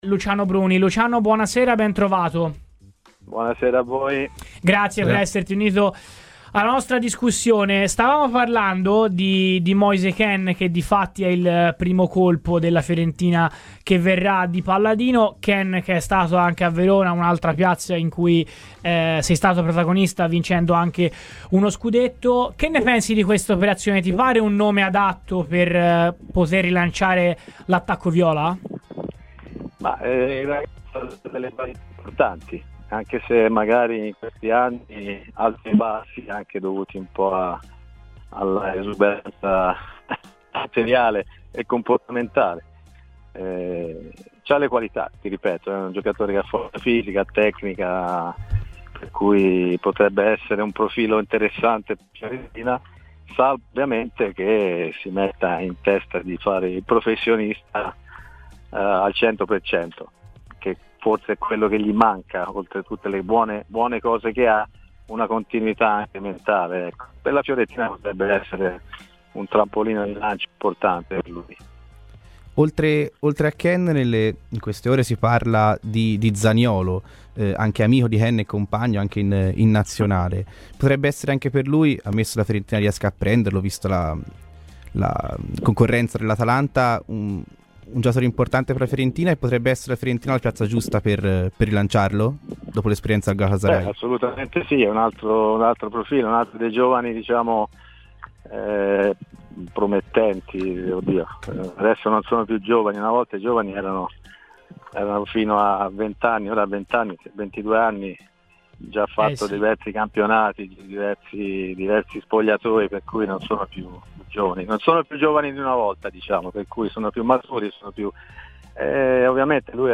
in diretta su Radio Firenzeviola..